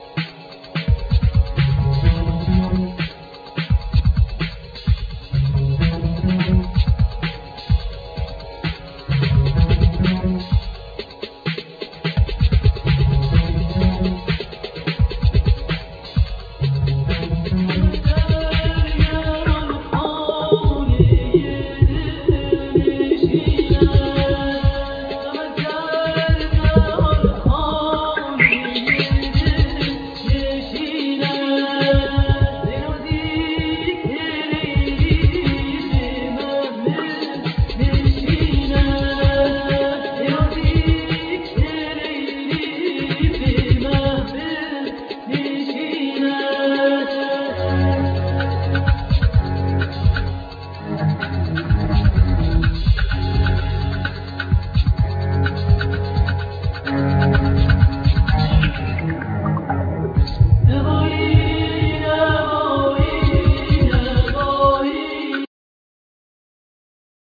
Vocals
Tabla
Tar, Setar, Kamanche, Ney